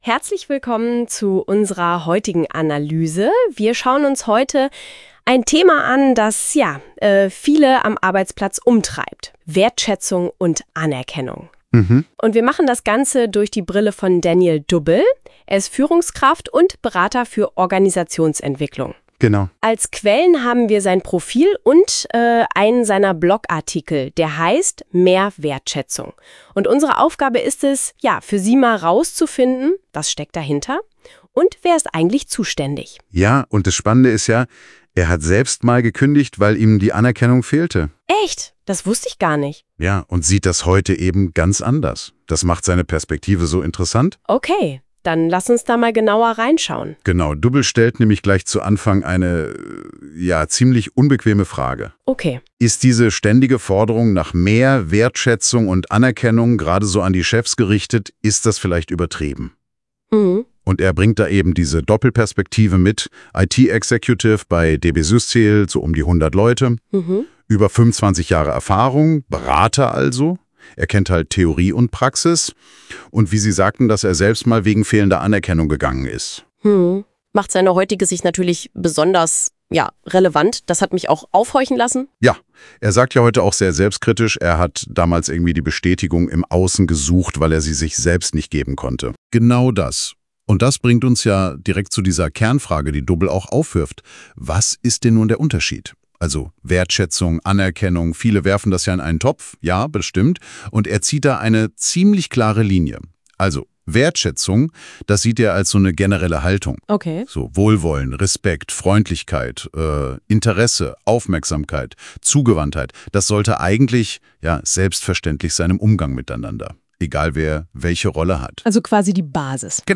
Hier bekommst du einen durch NotebookLM generierten KI-Podcast Dialog zu diesem Artikel.